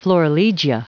Prononciation audio / Fichier audio de FLORILEGIA en anglais
Prononciation du mot florilegia en anglais (fichier audio)